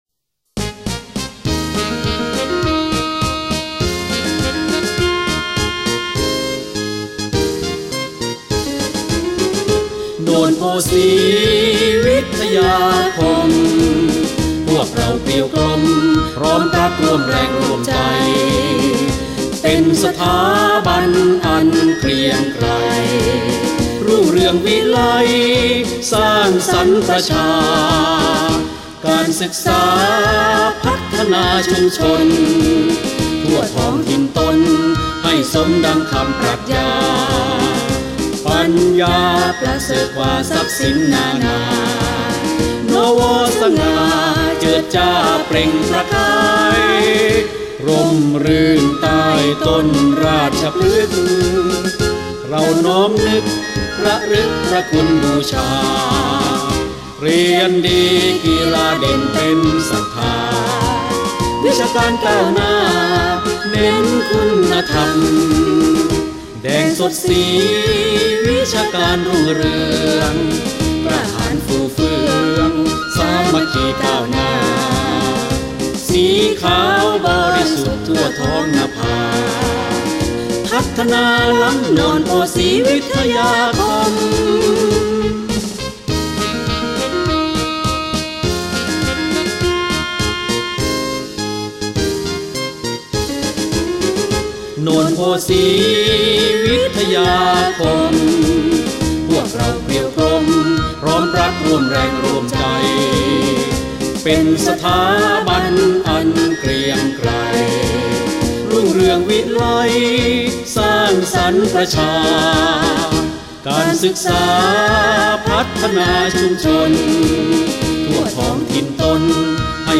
เพลงโรงเรียน